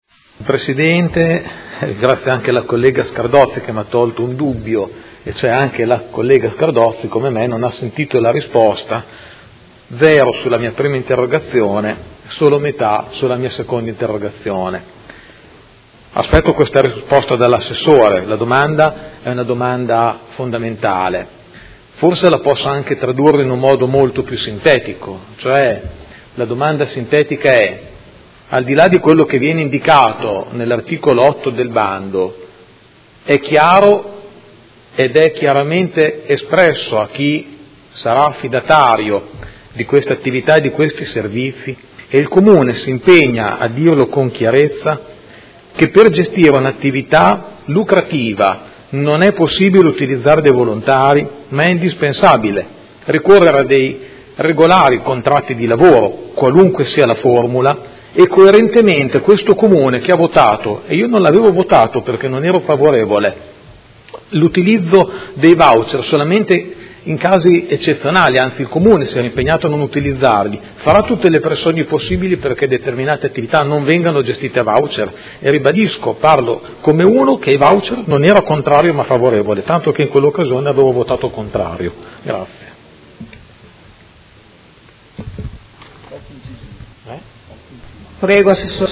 Giuseppe Pellacani — Sito Audio Consiglio Comunale
Seduta del 30/03/2017. Dibattito inerente le interrogazioni sul concerto di Vasco Rossi